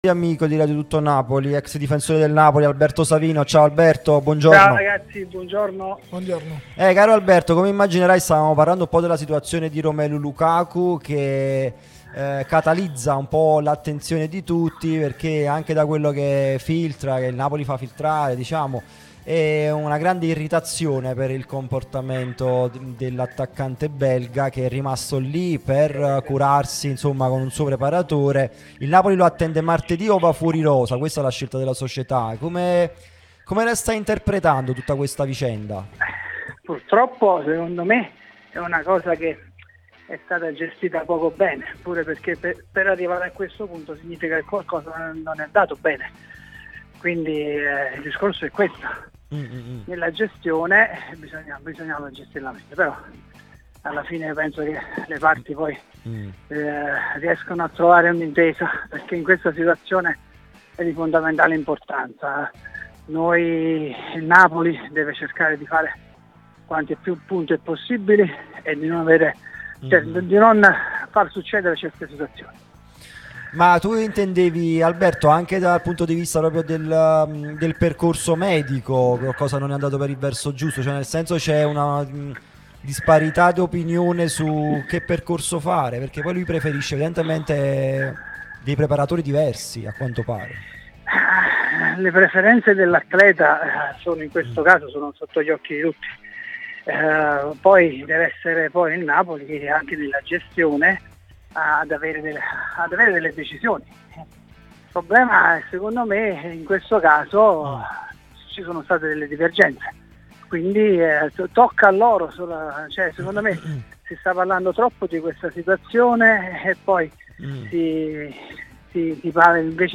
trasmissione sulla nostra Radio Tutto Napoli